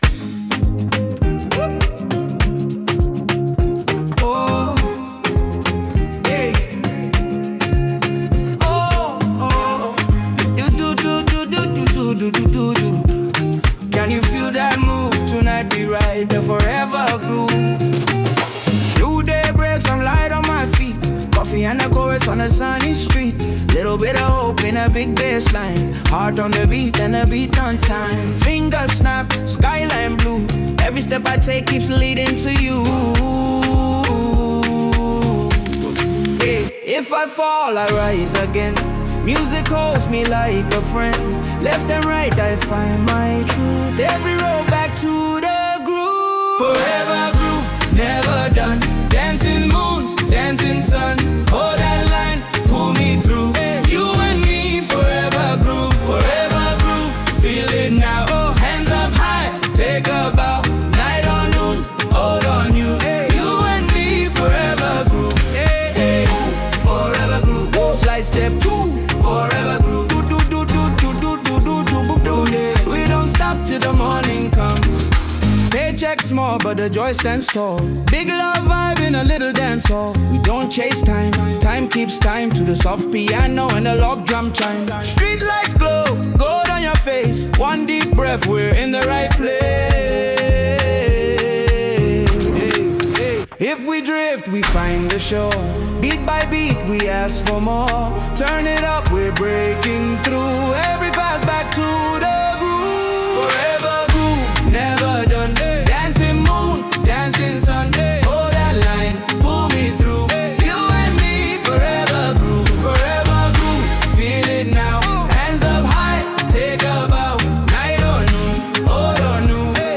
Afrobeats 2025 Non-Explicit